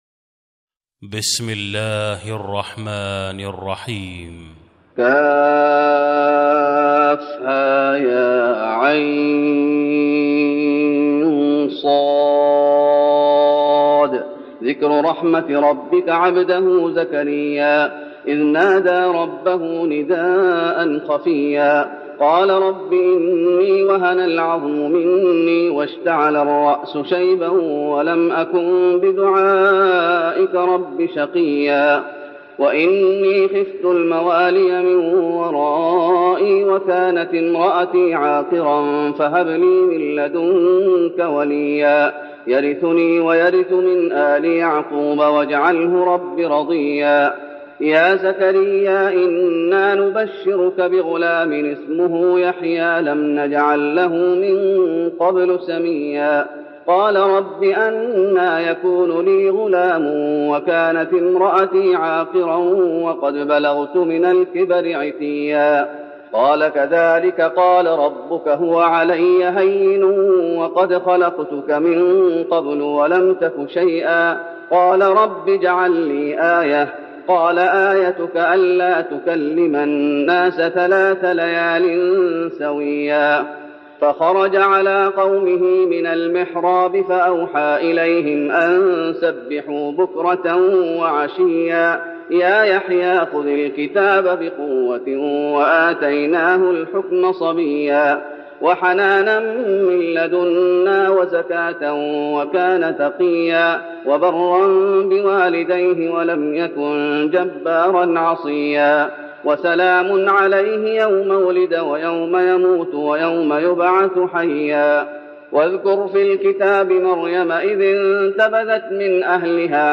تراويح رمضان 1412هـ سورة مريم Taraweeh Ramadan 1412H from Surah Maryam > تراويح الشيخ محمد أيوب بالنبوي 1412 🕌 > التراويح - تلاوات الحرمين